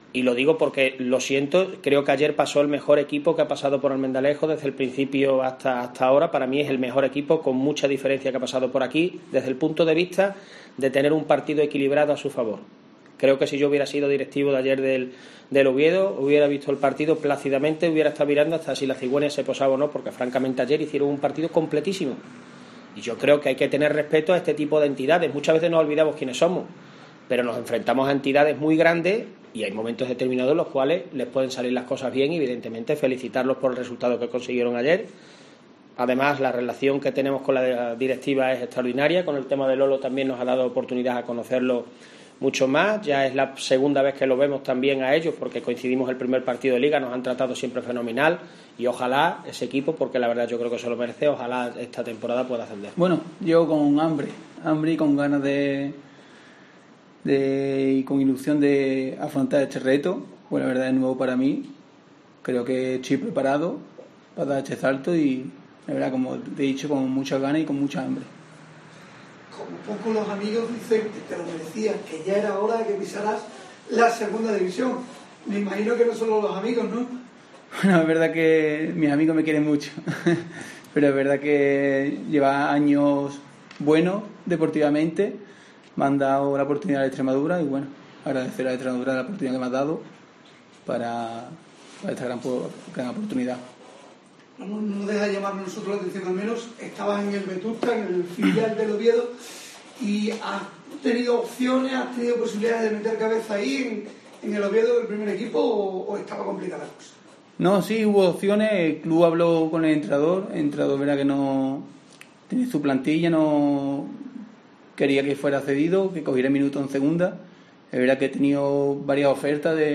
Presentación